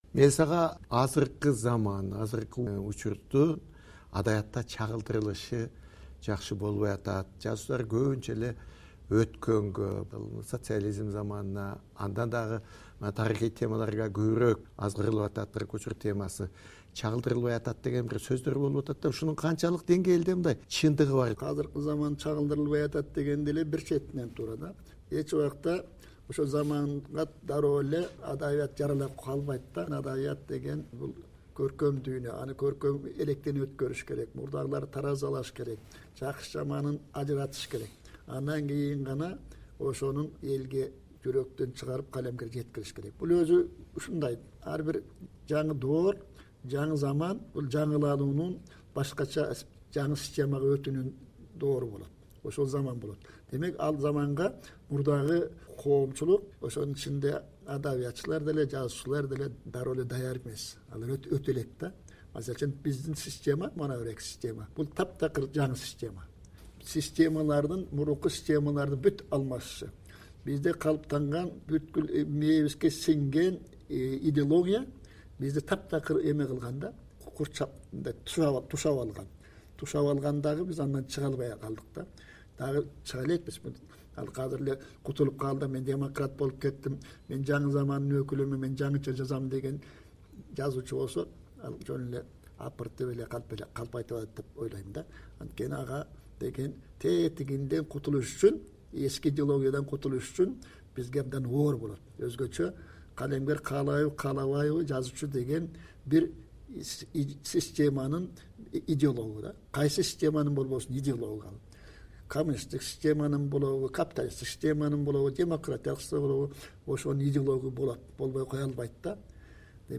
Жаңы доордун көркөм сүрөтүнүн адабиятта чагылдырылышы тууралуу пикирин сурап жазуучуну кепке тарттык.